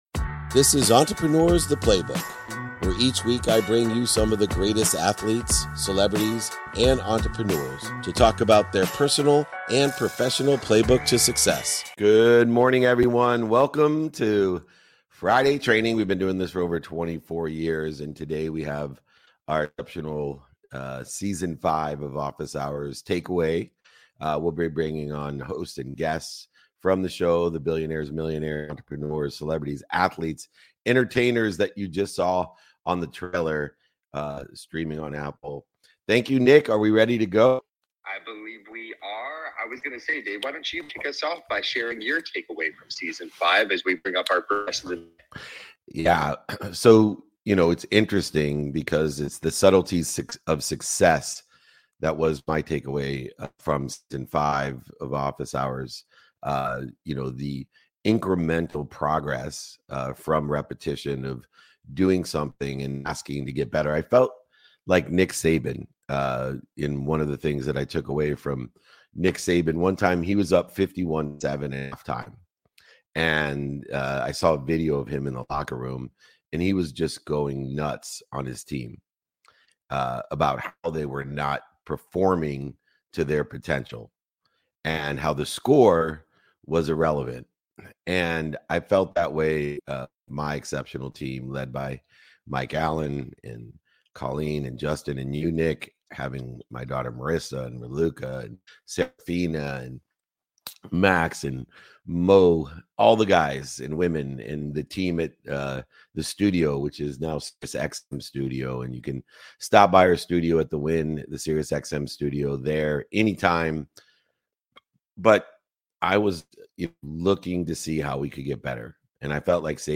In today’s episode, I’m thrilled to welcome a diverse group of guests, including a vibroacoustic therapy practitioner, a champion athlete, and leading entrepreneurs who share their incredible journeys and key insights into achieving success.